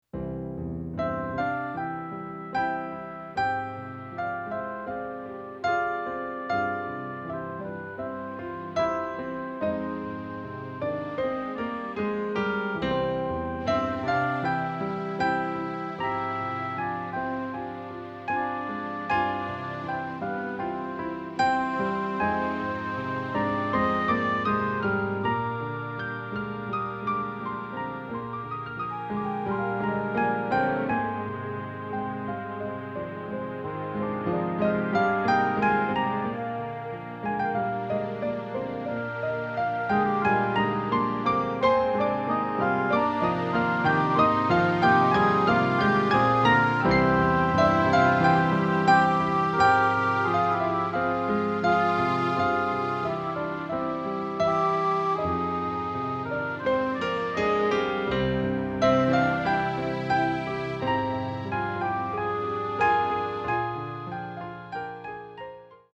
soundtrack instrumental. Slow but sweeping.